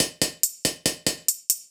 Index of /musicradar/ultimate-hihat-samples/140bpm
UHH_ElectroHatD_140-03.wav